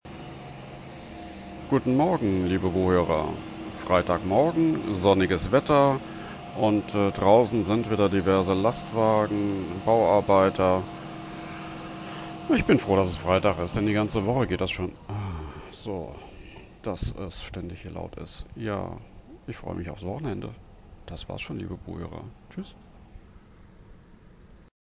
Lärm, Wochenende
52470-larm-wochenende.mp3